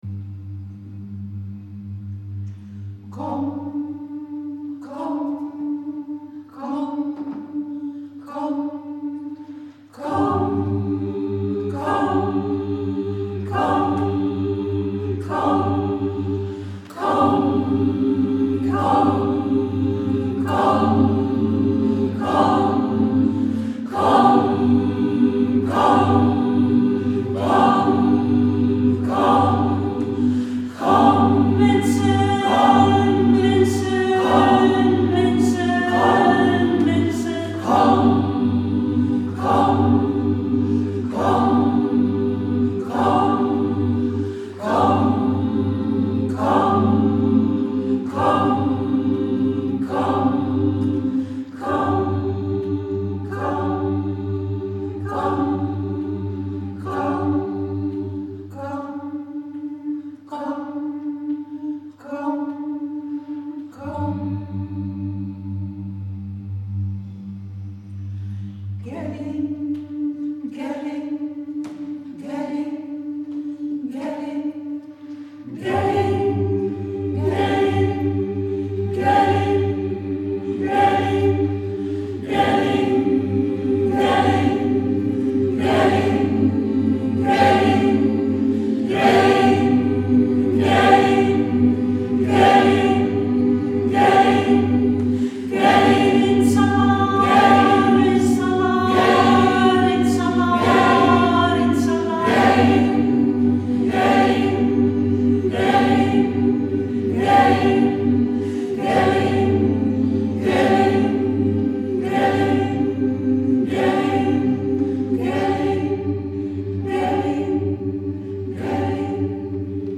Call for Art is het langstlopende project van het Van Abbemuseumkoor.
Voor Call wilde ze een geluid dat niet van boven naar beneden gaat, maar langzaam breder en smaller wordt en zich niet 'verticaal' maar 'horizontaal' uitstrekt.
Het werd een stuk voor gemengd koor en solist.
Versterkt door vier luidsprekers in de toren, ieder in de richting van een van de vier windstreken, klonk Call over de stad: Kom, mensen kom!, in de drie talen die in 2014 het meest gesproken werden in Eindhoven: Nederlands, Engels en Turks.